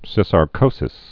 (sĭsär-kōsĭs)